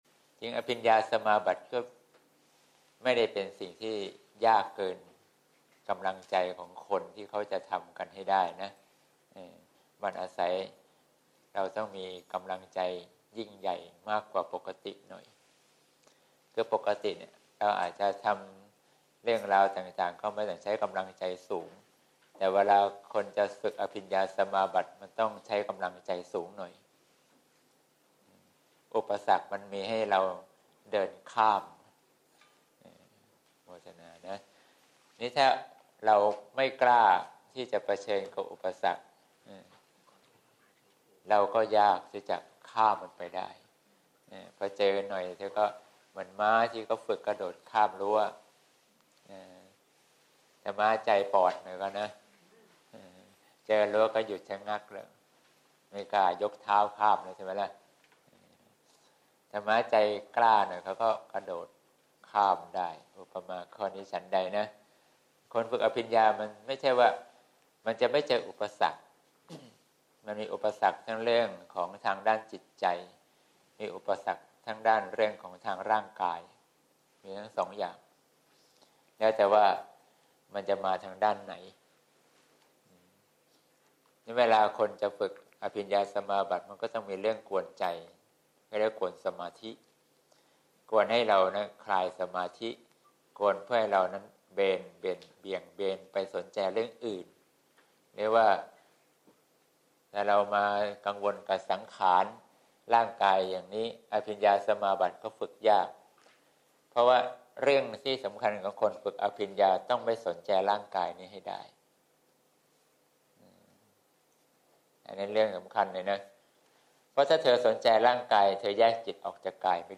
เสียงธรรม ๒๑ ธ.ค. ๖๗